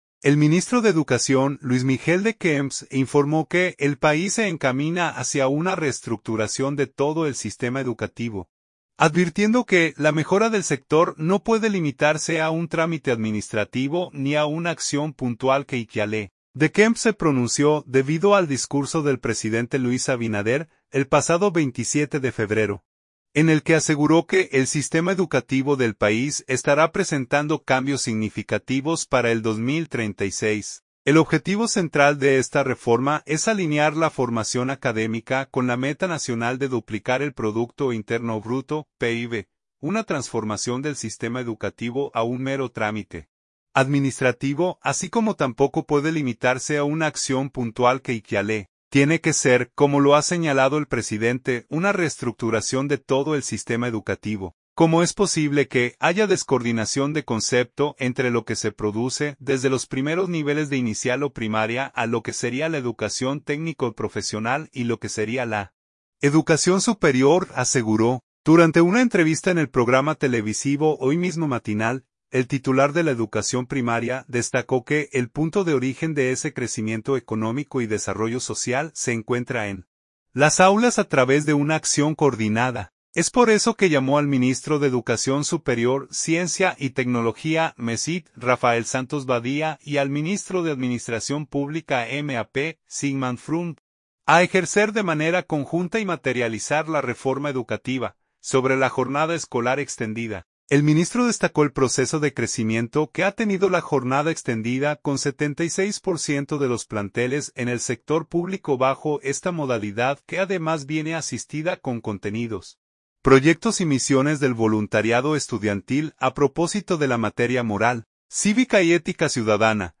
“Una transformación del sistema educativo a un mero trámite administrativo, así como tampoco puede limitarse a una acción puntual curricular, tiene que ser, como lo ha señalado el presidente, una reestructuración de todo el sistema educativo. ¿Cómo es posible que haya descoordinación de concepto entre lo que se produce desde los primeros niveles de inicial o primaria a lo que sería la educación técnico-profesional y lo que sería la educación superior?”, aseguró, durante una entrevista en el programa televisivo Hoy Mismo Matinal.